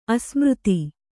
♪ asmřti